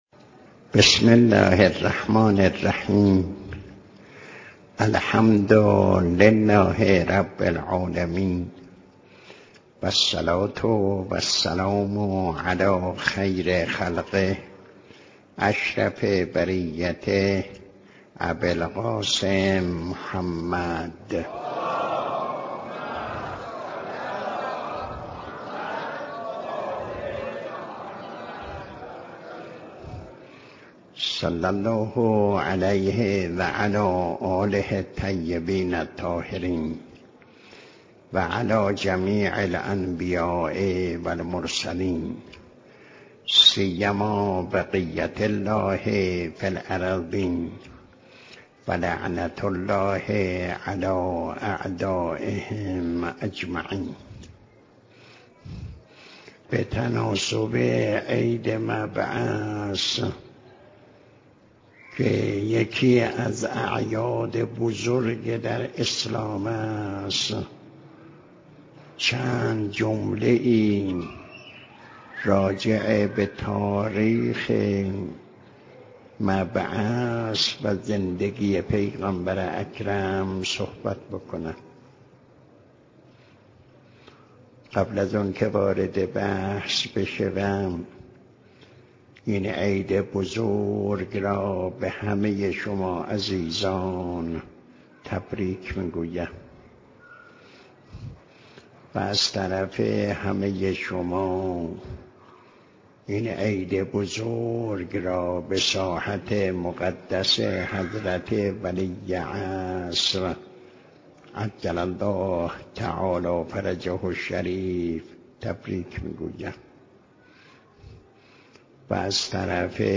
بیانات ارزشمند آیت الله حسین مظاهری درباره ی «هدف و نتیجه بعثت و رسالت»